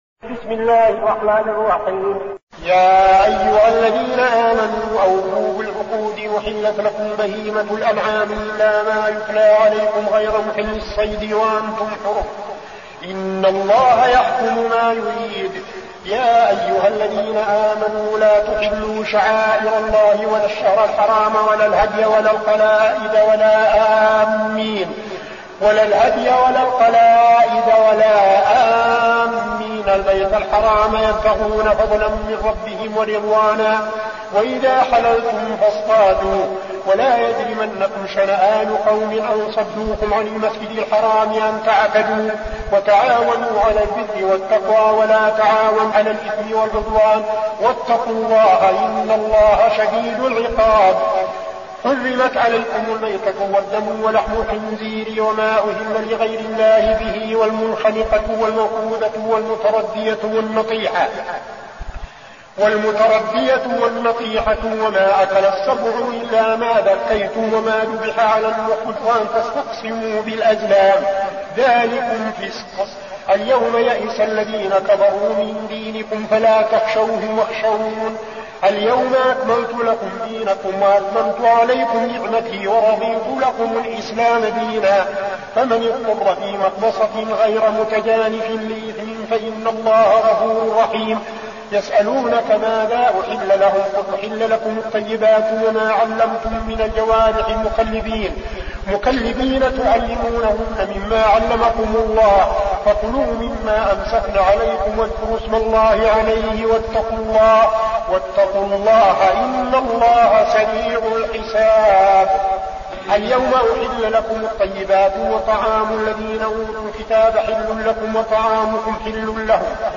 المكان: المسجد النبوي الشيخ: فضيلة الشيخ عبدالعزيز بن صالح فضيلة الشيخ عبدالعزيز بن صالح المائدة